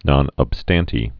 (nŏn əb-stăntē, -stän-, nōn)